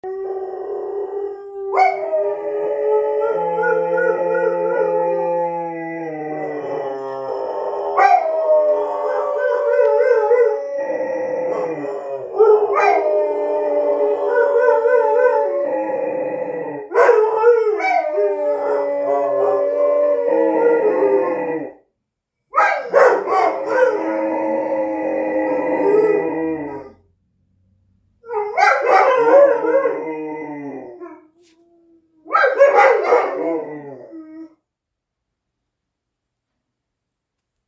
Az egész akkor kezdődött, amikor egy telefonos felvételről visszajátszottam a kutyák éneklését.
Léna és Daisy éneklése
• Csatornák száma: 1 (mono felvétel)
Dalmata és Moszkvai őrkutya énekel
• Átlagos domináns frekvencia: 521,61 Hz
A spektrumban látható, hogy a hangok főként az alacsonyabb és közepes frekvenciatartományban vannak, ami tipikus lehet a kutyák „éneklésére”.
Eneklo-kutyak.mp3